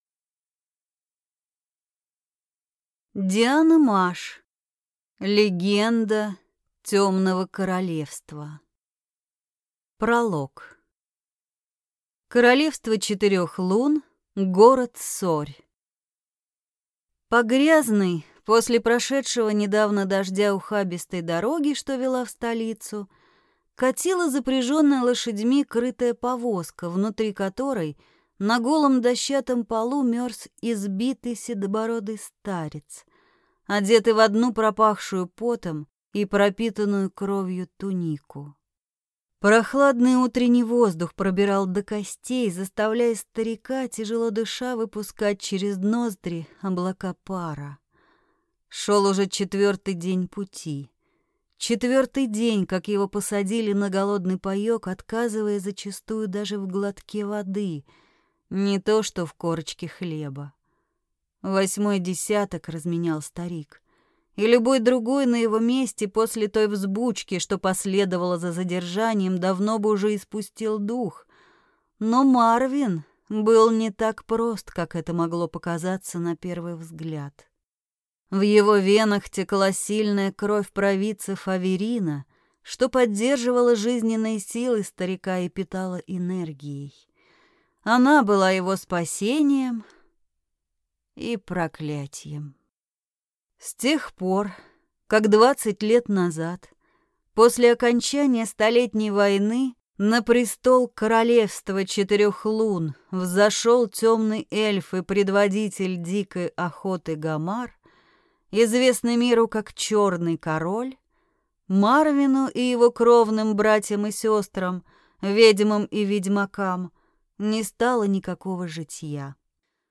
Аудиокнига Легенда темного королевства | Библиотека аудиокниг